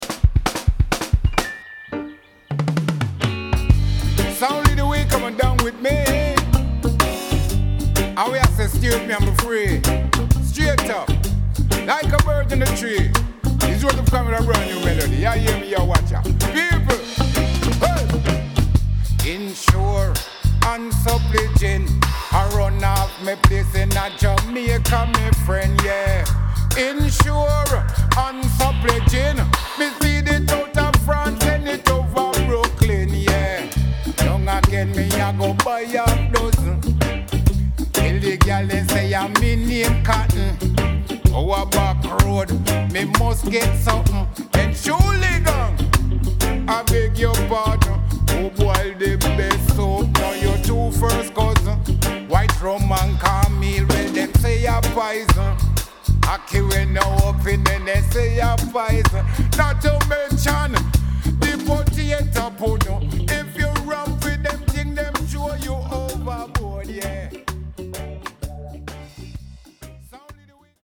Vocals recorded in Paris.